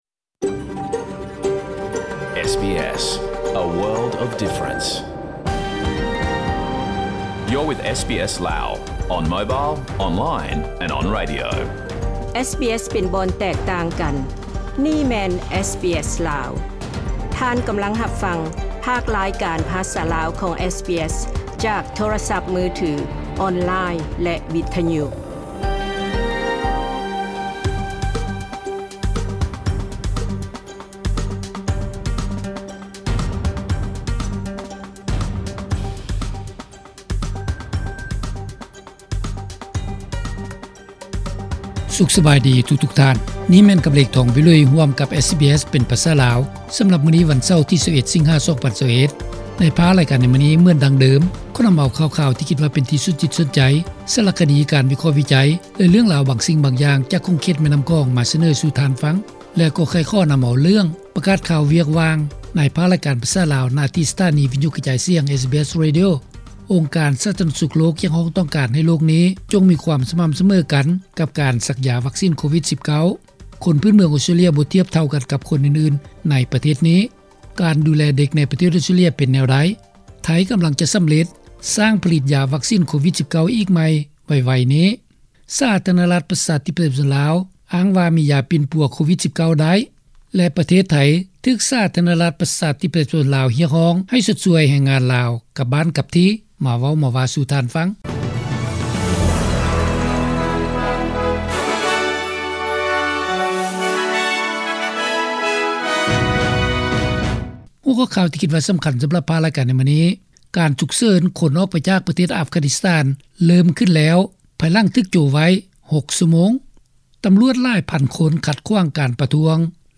ຂ່າວສຳຮັບ 21-8-21